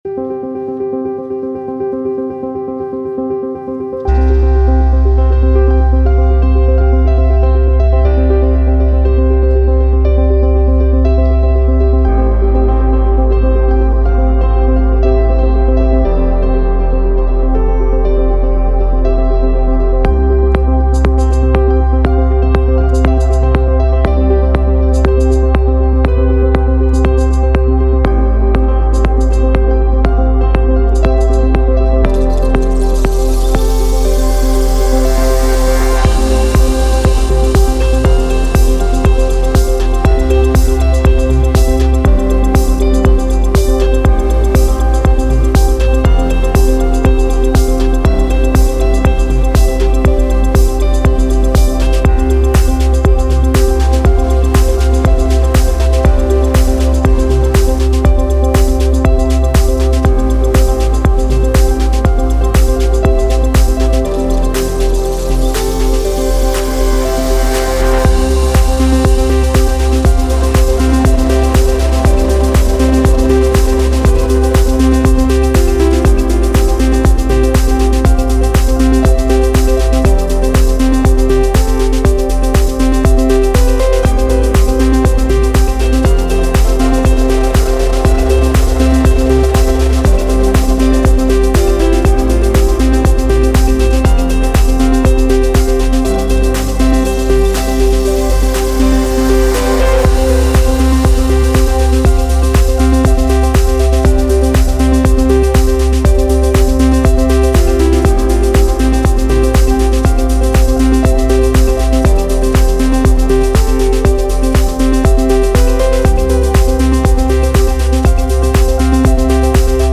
Drum, Piano, Synth